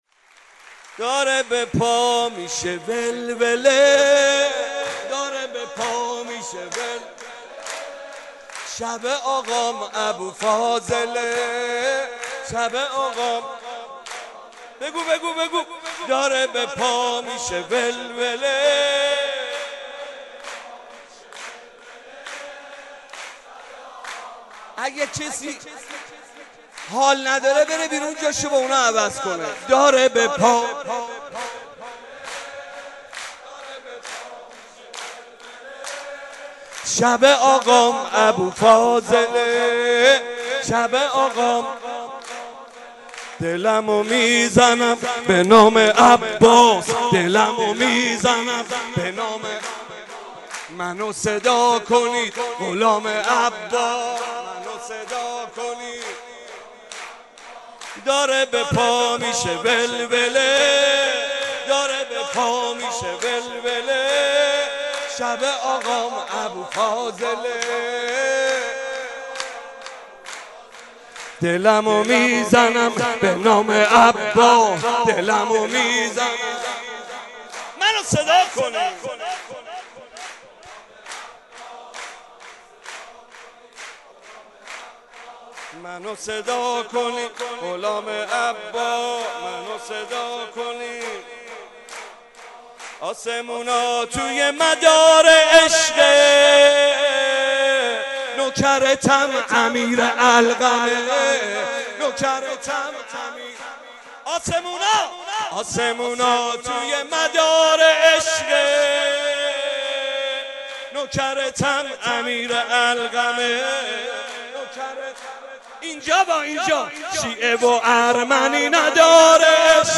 مولودی
بمناسبت میلاد باسعادت حضرت ابالفضل (ع)